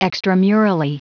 Prononciation du mot : extramurally
extramurally.wav